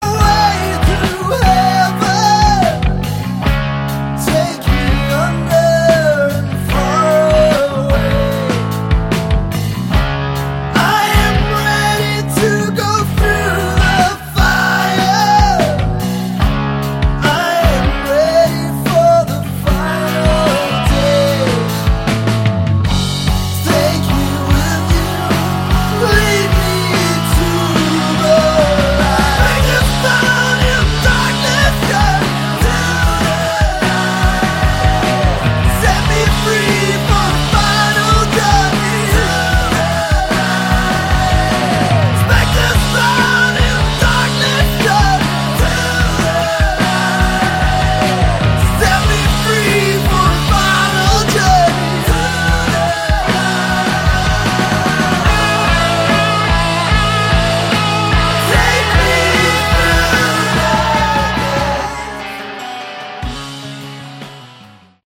Category: Hard Rock
drums
guitars
vocals, bass